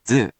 We’re going to show you the character, then you you can click the play button to hear QUIZBO™ sound it out for you.
In romaji, 「づ」 is transliterated as 「dzu」or 「du」which sounds sort of like a cross between the English words「do」and 「zoo」 depending on the dialect which ranges from /dzɯ/ to /zɯ/ **